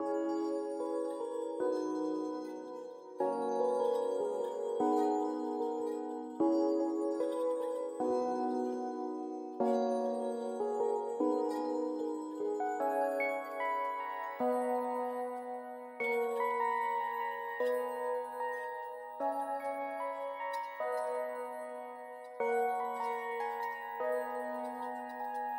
Dark Bell Melody
描述：Bells i made in garageband
标签： 150 bpm Trap Loops Bells Loops 4.31 MB wav Key : Unknown Garageband
声道立体声